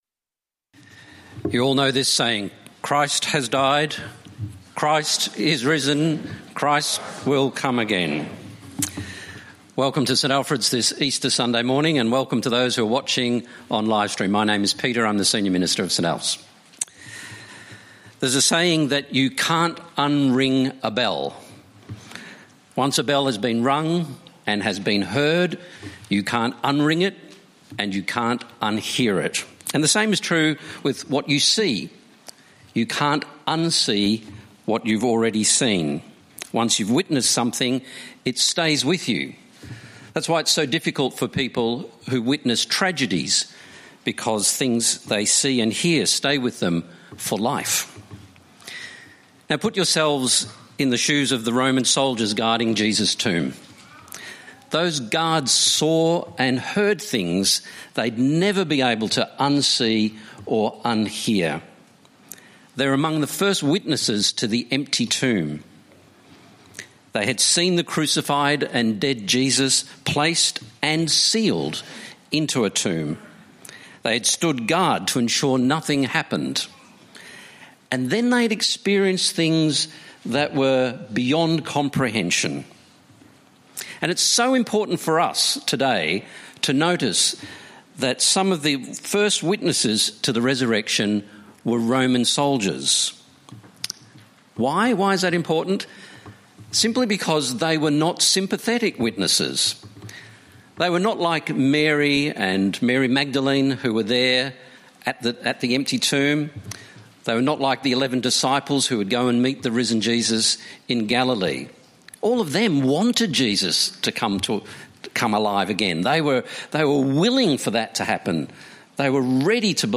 Download Download Bible Passage Matthew 28:1-20 In this sermon